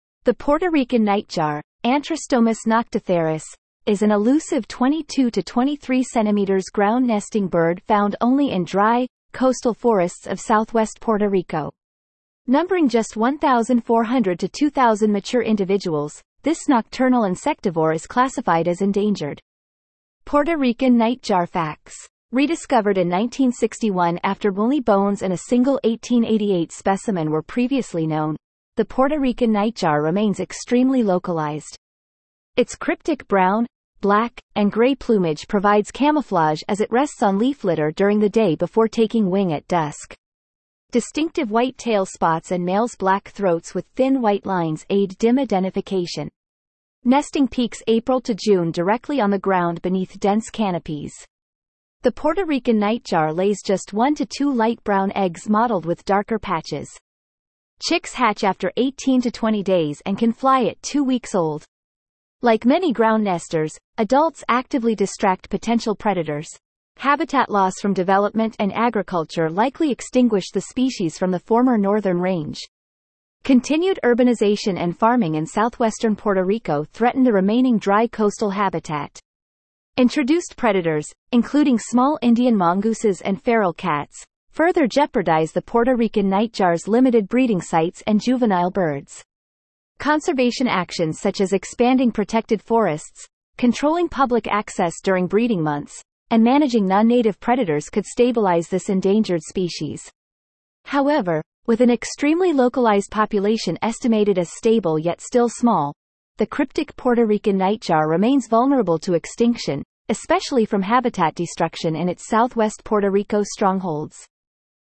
Puerto Rican Nightjar
Puerto-Rican-Nightjar.mp3